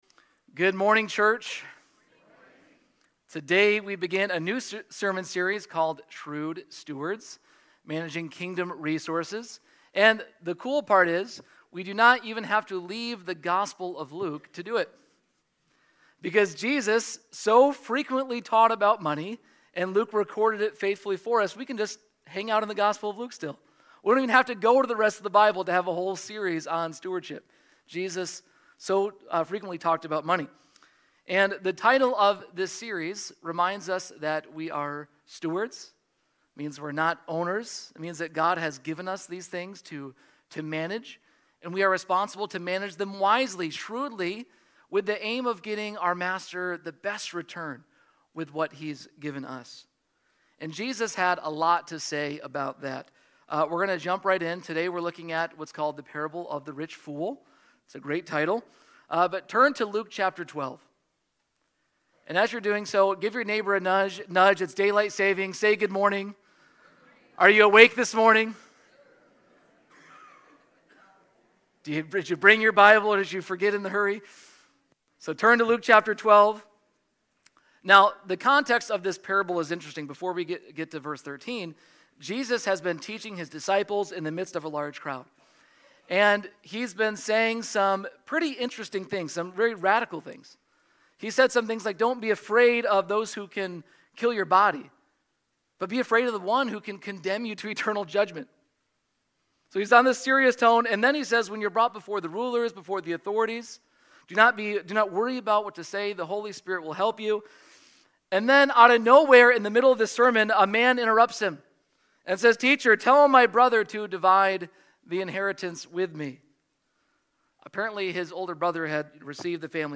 Sermons | Faith Covenant Church